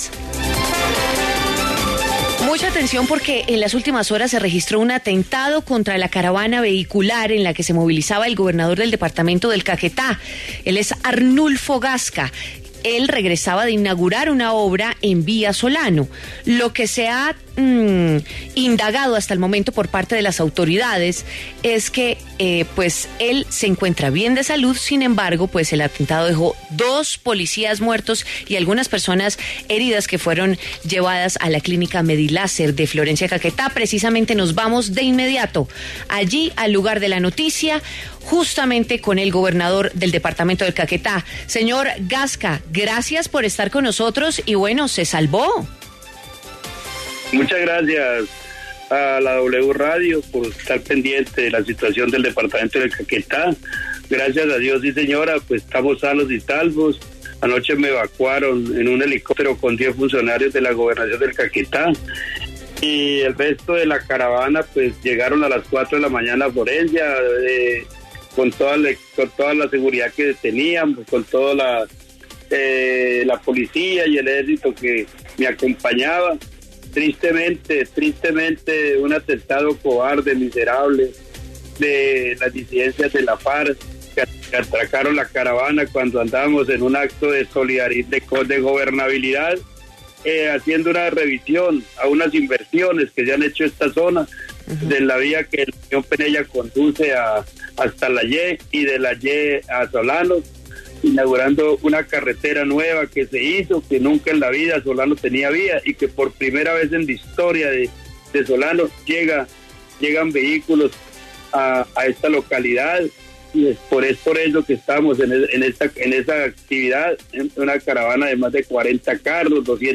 Arnulfo Gasca, gobernador de Caquetá, habló en W Fin de Semana sobre el atentado que sufrió y donde murieron dos policías.